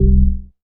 ORGAN-07.wav